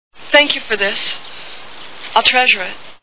A Fish Called Wanda Movie Sound Bites